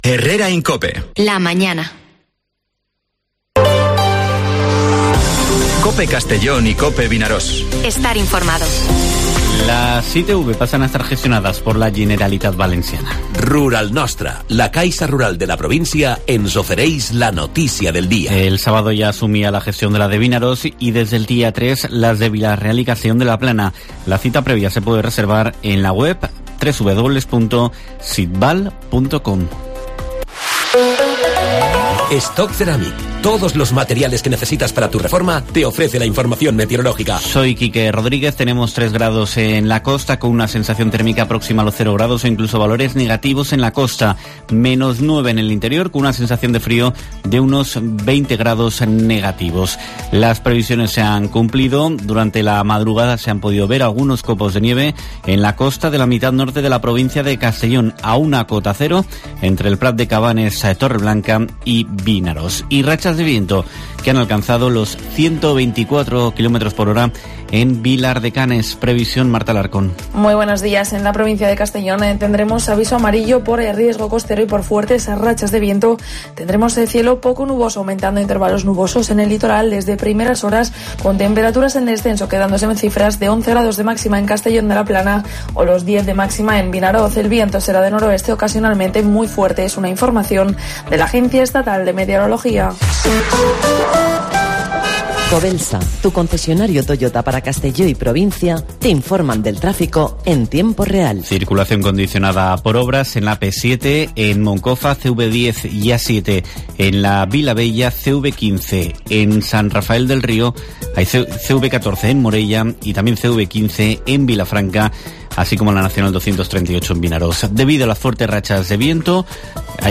Noticias